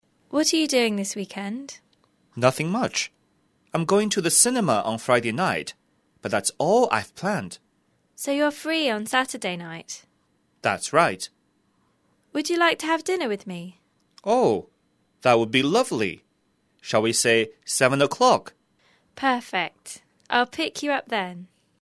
english_30_dialogue_2.mp3